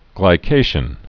(glī-kāshən)